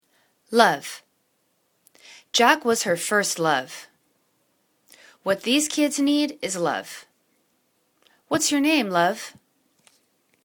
love    /luv/    n